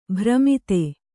♪ bhramite